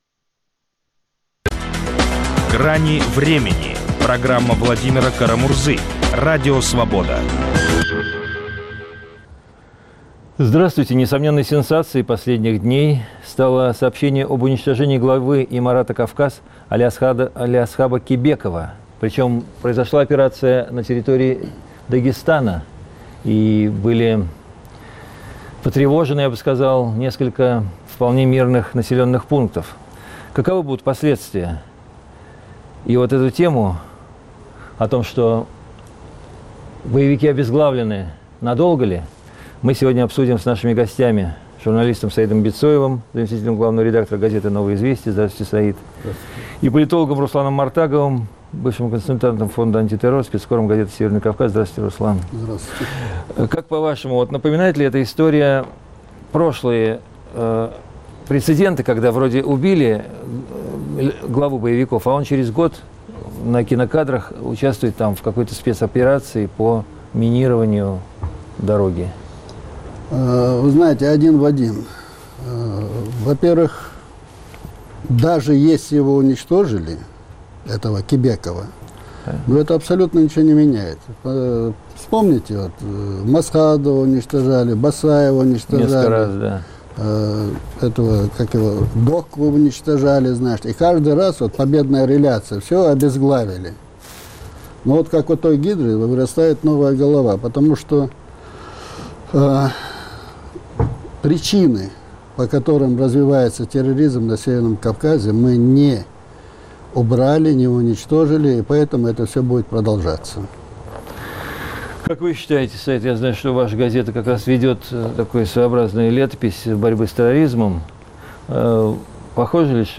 Российские СМИ сообщили об уничтожении главы "Имарата Кавказ" - Алиасхаба Кебекова. Каковы будут последствия? - обсуждают журналисты